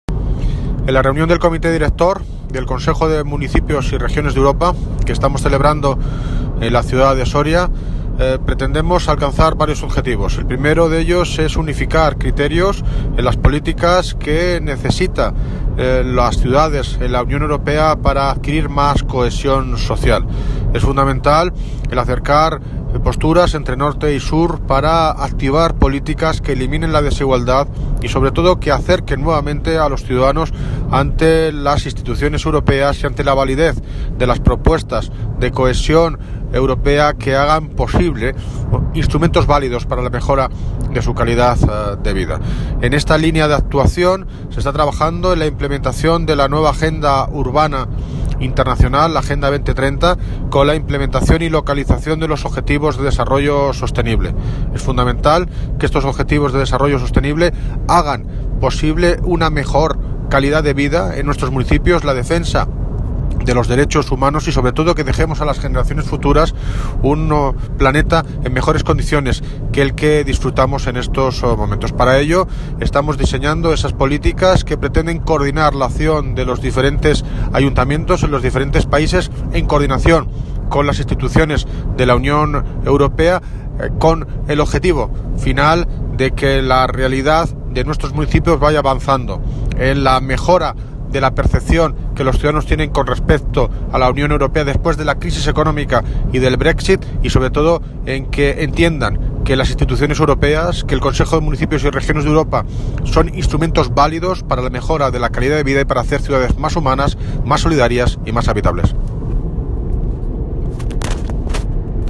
Audio - David Lucas (Alcalde de Móstoles) Sobre Comité Director del Consejo de Municipios y Regiones de Europa